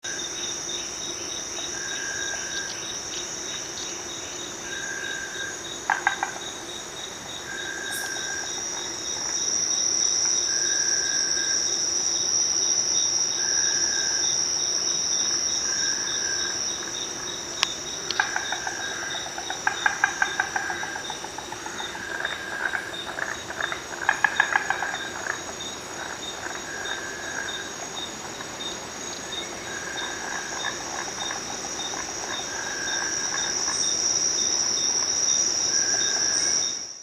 Play call
At such times their distinctive call, which sounds like two hollow sticks being knocked together, may be heard late afternoon or after dusk.
rhacophorus -norhayatiae.mp3